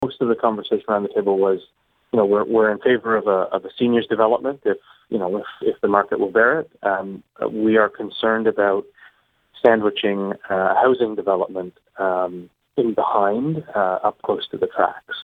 At council’s planning committee Wednesday night, Mayor Brian Ostrander says councillors liked the general idea of having mixed residential and commercial but they also had concerns.